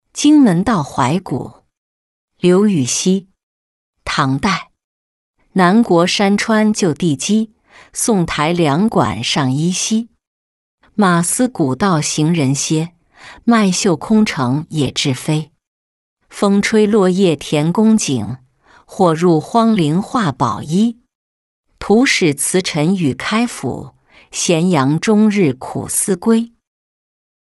荆门道怀古-音频朗读